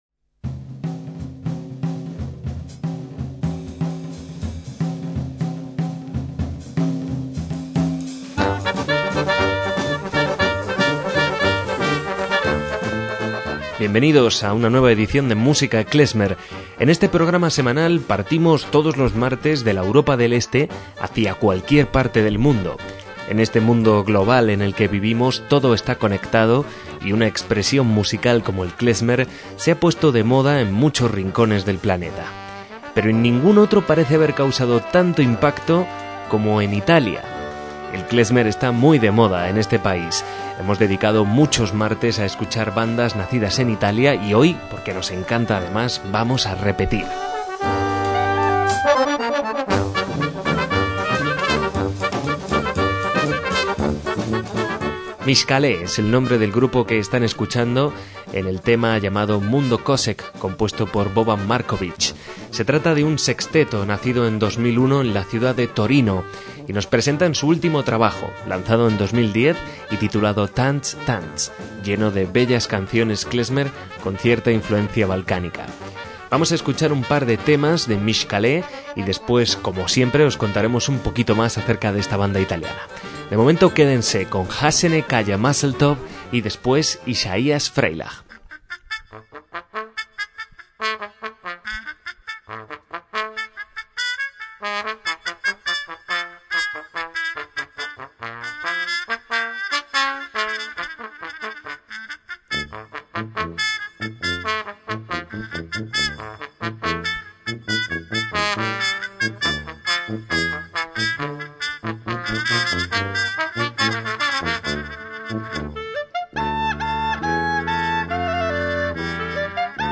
MÚSICA KLEZMER
una música embriagadora, conmovedora y vertiginosa
clarinete
trompeta y fiscornio
trombón
acordeón
batería y percusiones
tuba